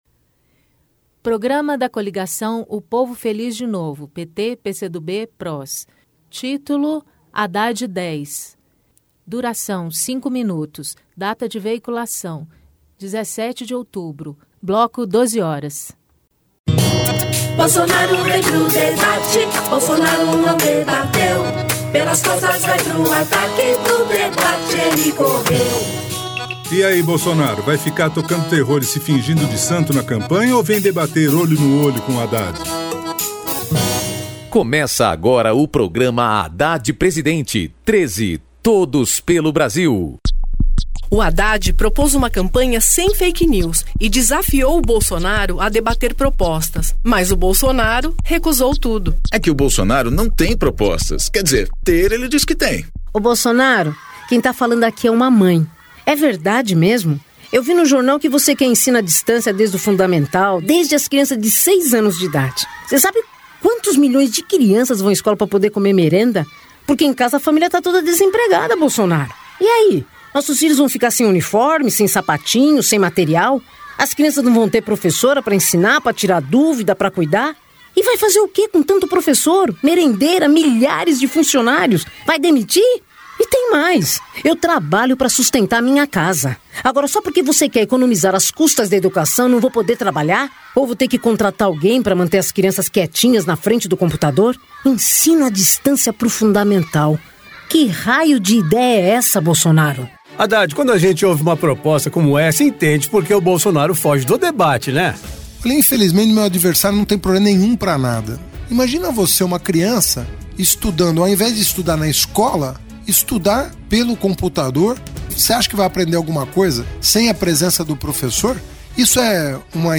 Gênero documentaldocumento sonoro